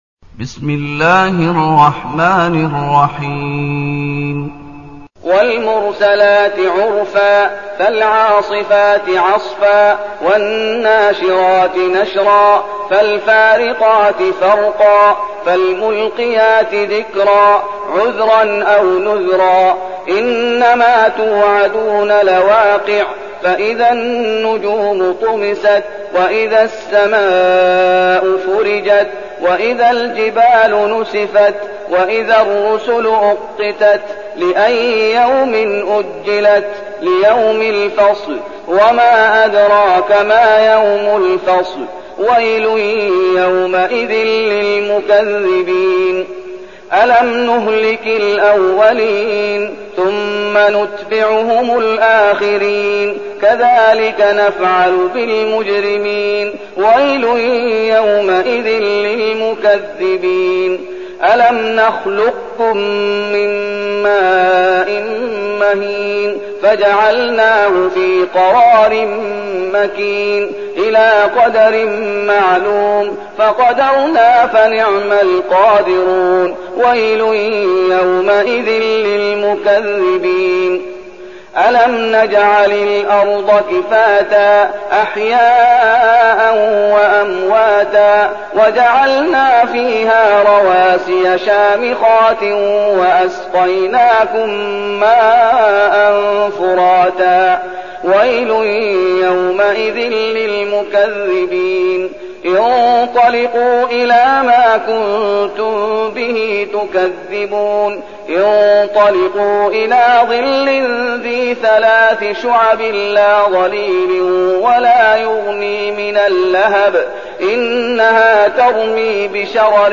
المكان: المسجد النبوي الشيخ: فضيلة الشيخ محمد أيوب فضيلة الشيخ محمد أيوب المرسلات